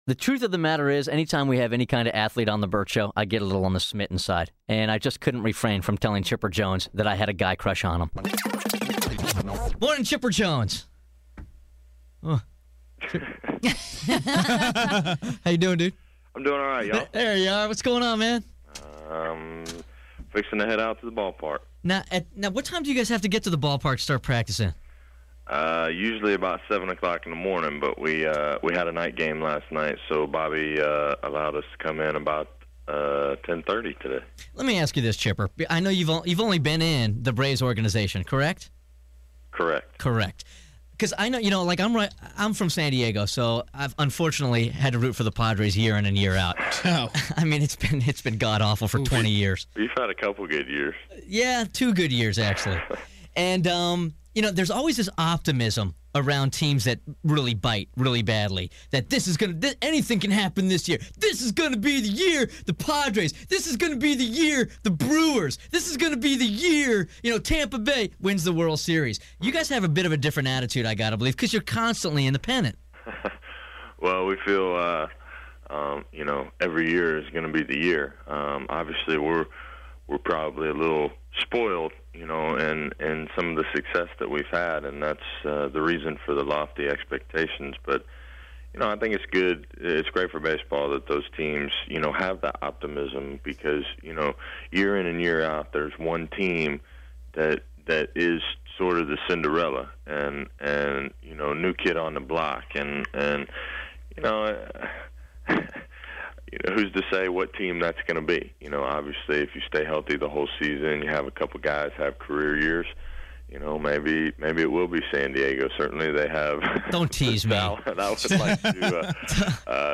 Vault: Interview With Chipper Jones